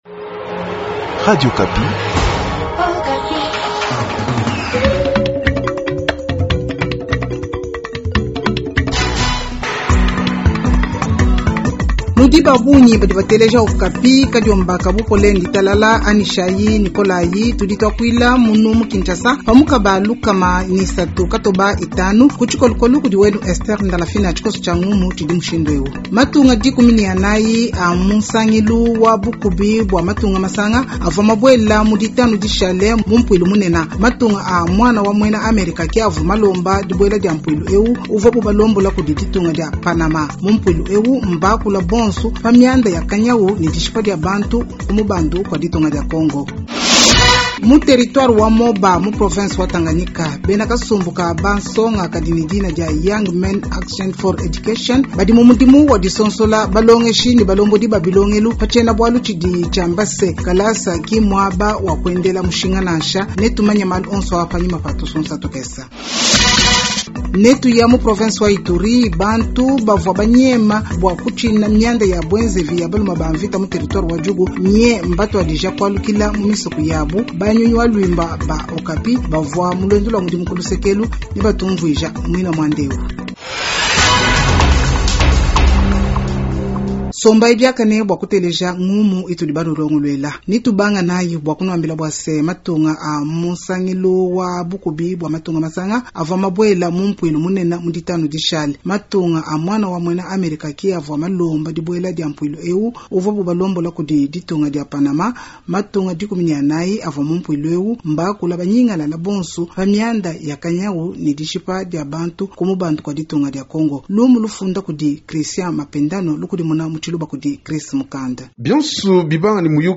Journal tshiluba du lundi 24 Aout 2025